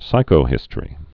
(sīkō-hĭstə-rē)